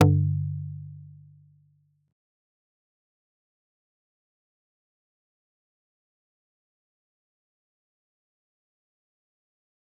G_Kalimba-G2-pp.wav